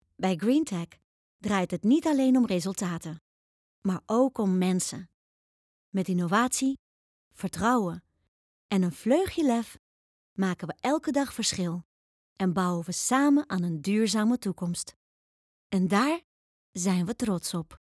Opvallend, Speels, Veelzijdig, Vriendelijk, Warm
Corporate